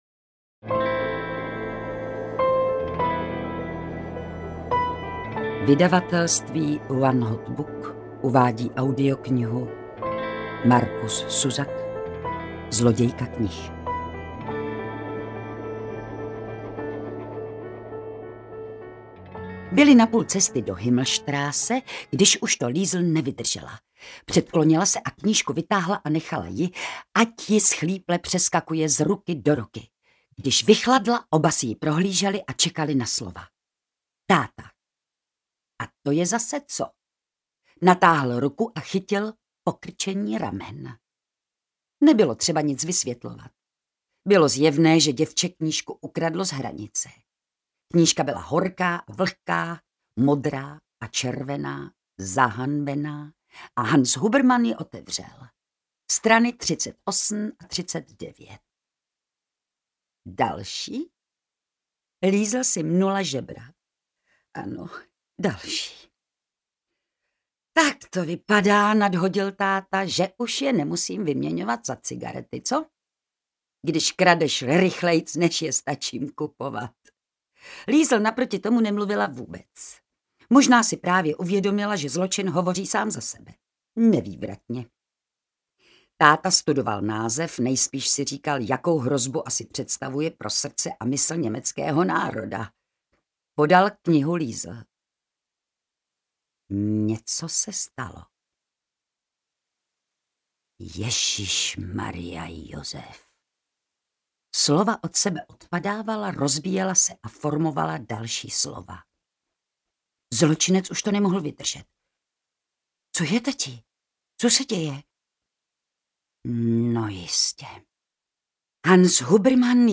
Zlodějka knih audiokniha
Ukázka z knihy
• InterpretVilma Cibulková
zlodejka-knih-audiokniha